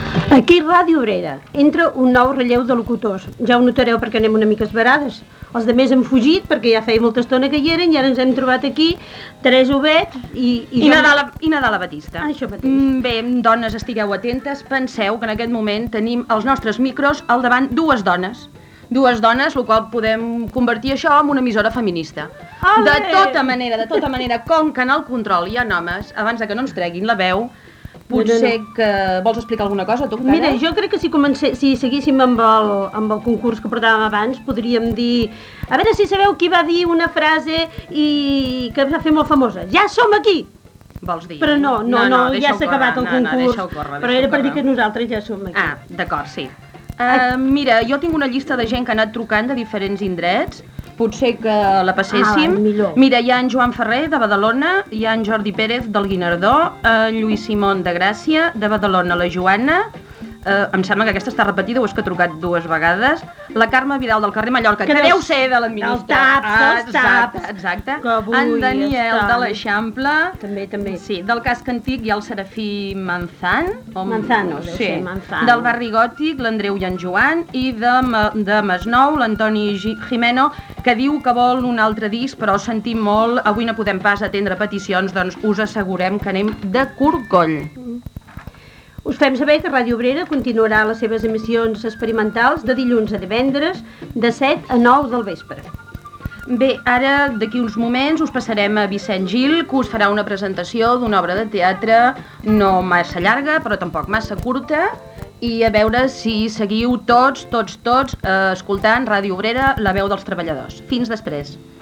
FM
Primer dia d'emissió.